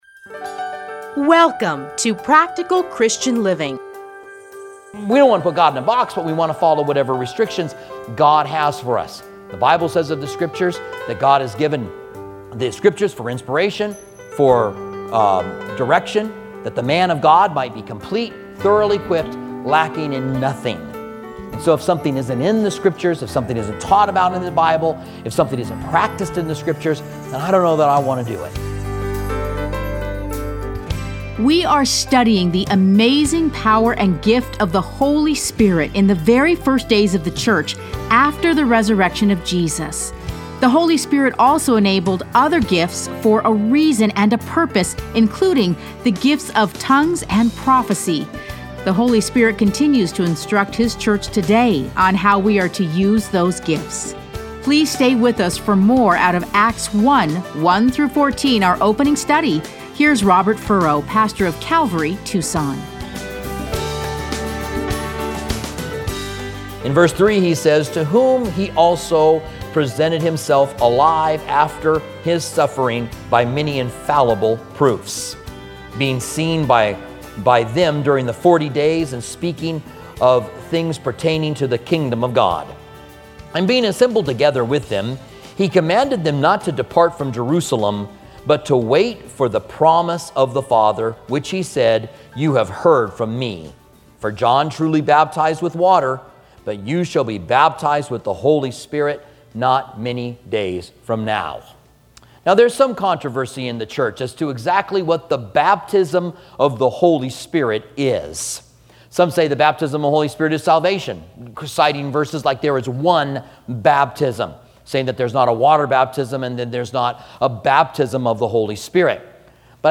Listen to a teaching from Acts 1:1-14.